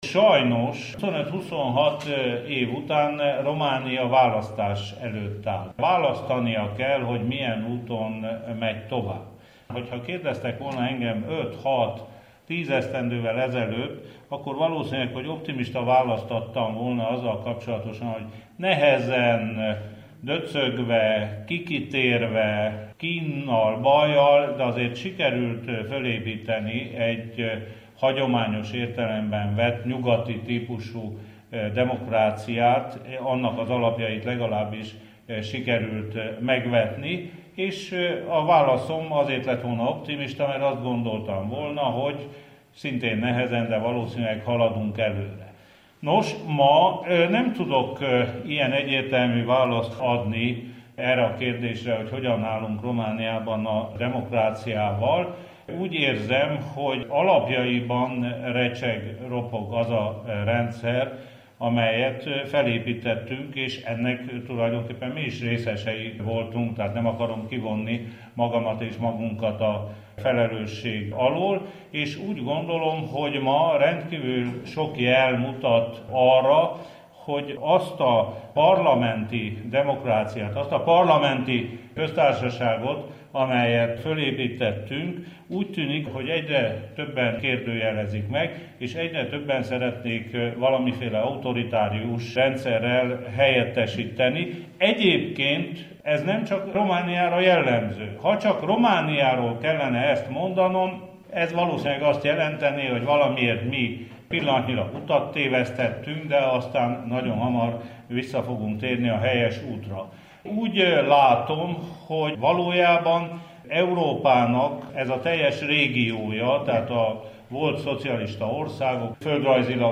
Markó Béla helyzetértékelő előadása
Immár hagyománnyá vált, hogy Markó Béla szenátor helyzetértékelő előadásával kezdődnek a Bukaresti Magyar Napok. A következő percekben Markó Béla előadását hallják a romániai választásokról.